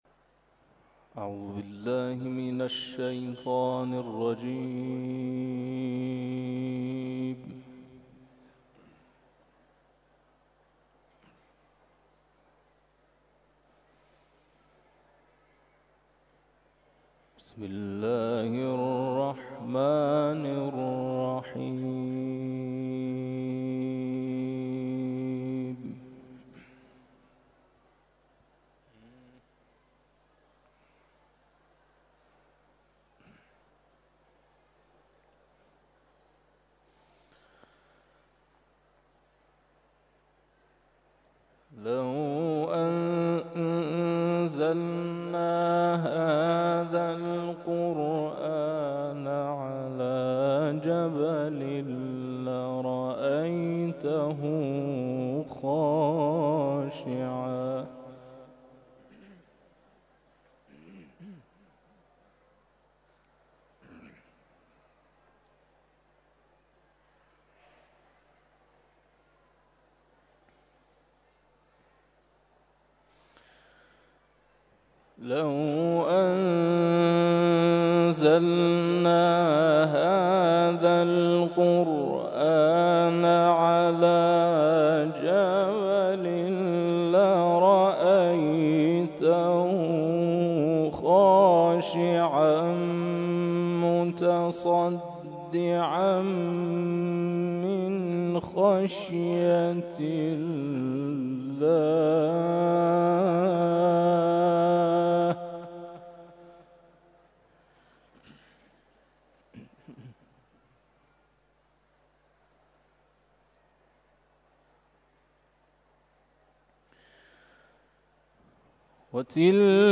تلاوت ظهر - سوره غافر آیات ( ۵۹ الی ۶۵) و سوره قدر Download
تلاوت مغرب - سوره حشر آیات ( ۲۱ الی ۲۴) Download